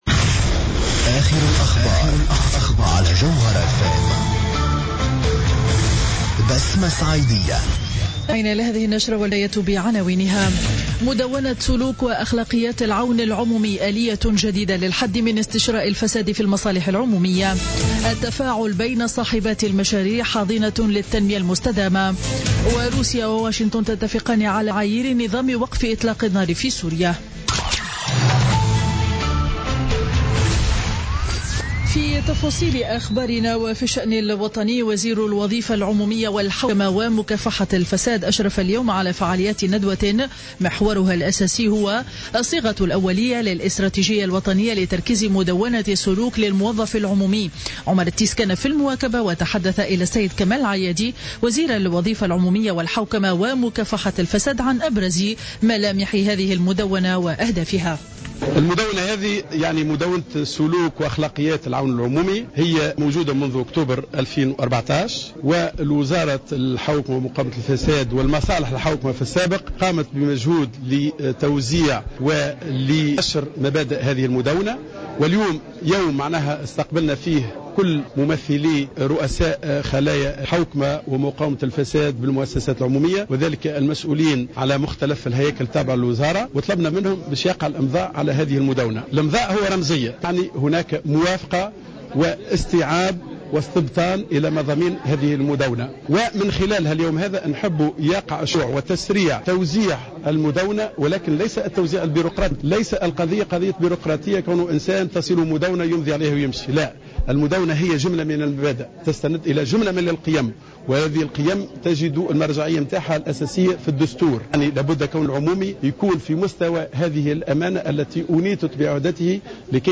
نشرة أخبار منتصف النهار ليوم الاثنين 22 فيفري 2016